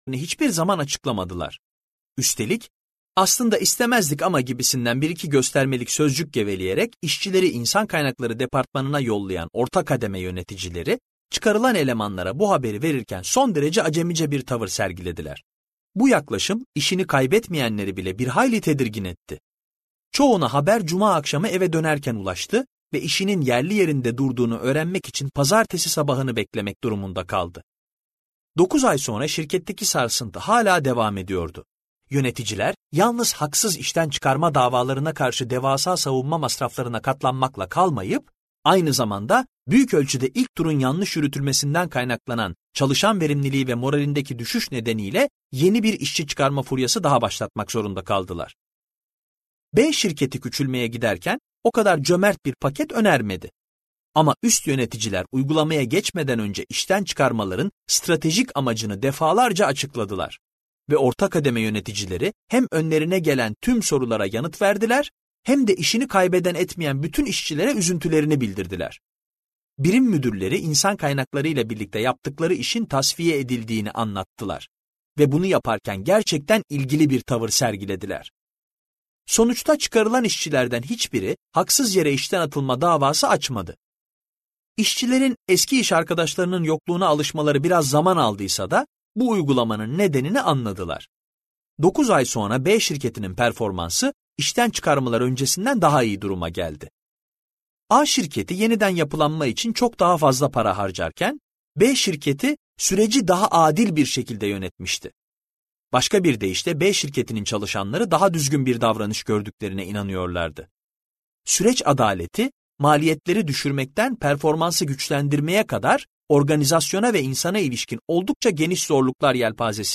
Dinleyeceğiniz bu makale HBR’s 10 Must Reads serisinde ”Duygusal Zeka” konulu kitapta yer alır ve Harvard Business Review dergisinde ilk olarak Mart 2006’da yayınlanmıştır.